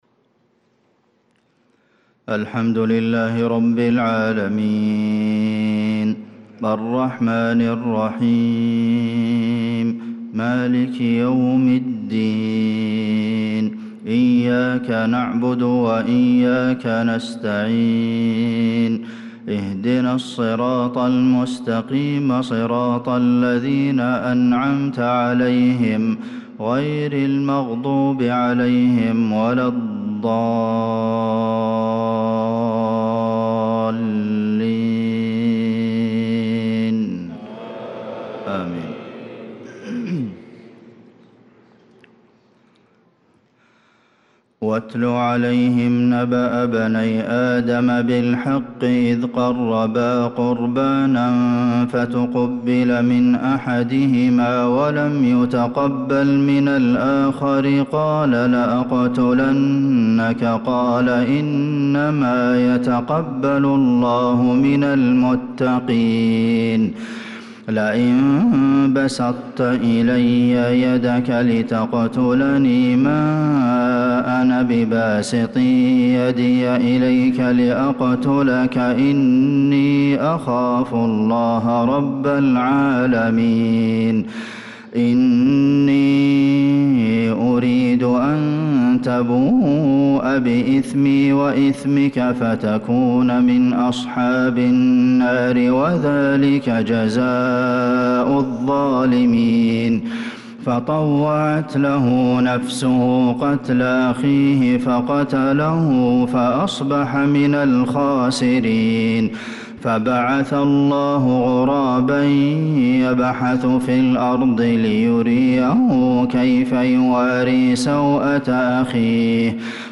صلاة الفجر للقارئ عبدالمحسن القاسم 2 ذو القعدة 1445 هـ
تِلَاوَات الْحَرَمَيْن .